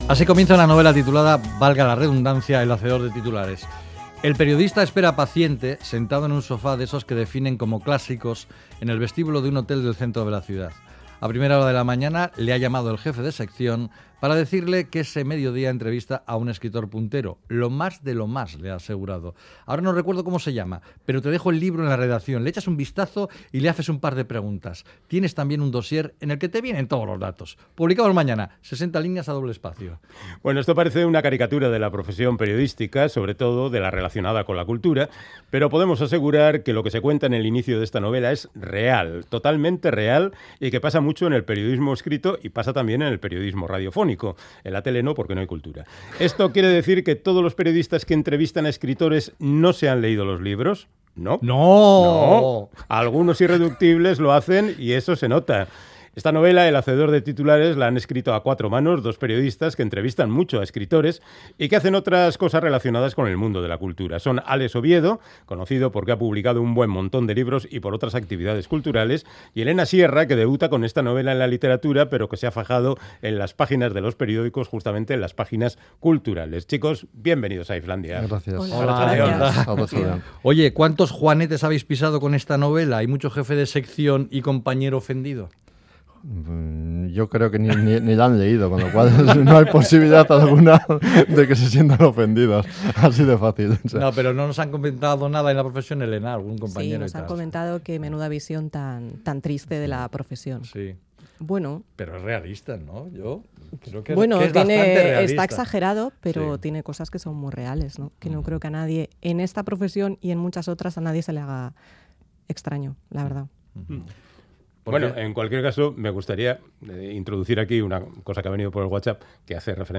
Charlamos con los periodistas